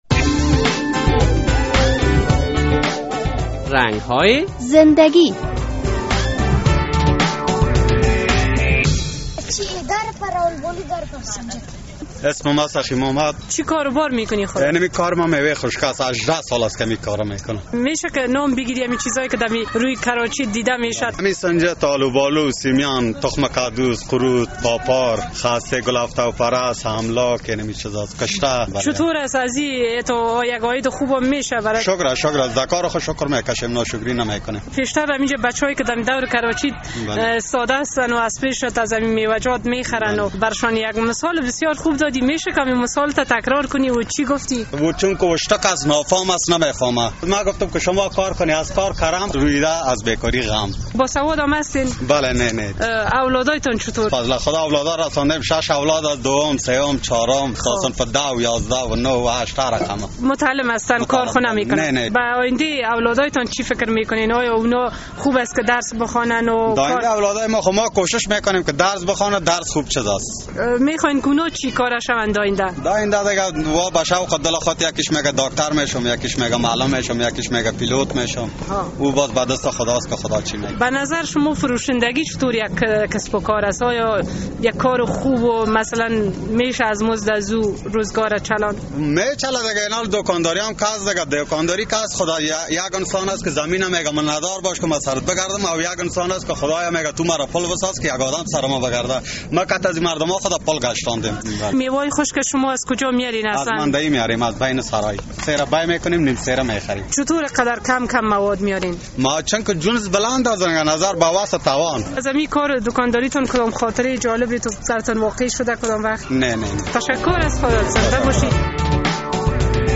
مصاحبهء کوتاه با یک تن از میوه فروشان در شهر کابل